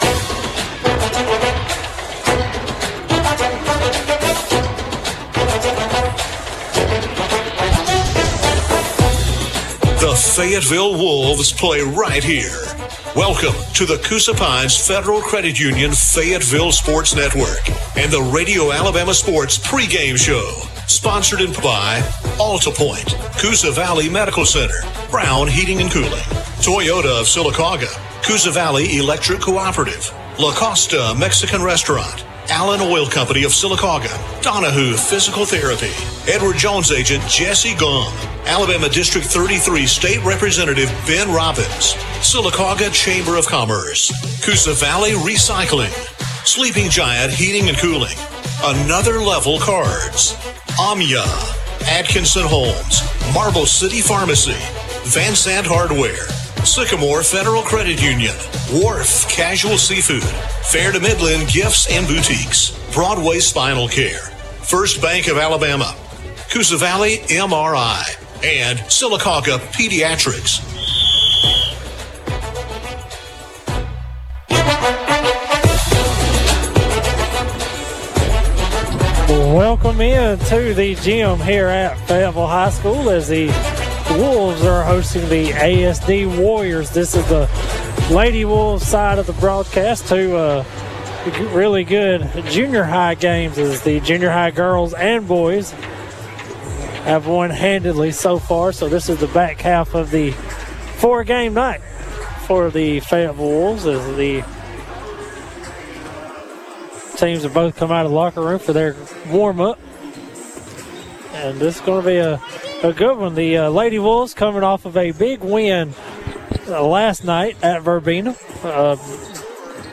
(Girls Basketball) Fayetteville vs. Alabama School for the Deaf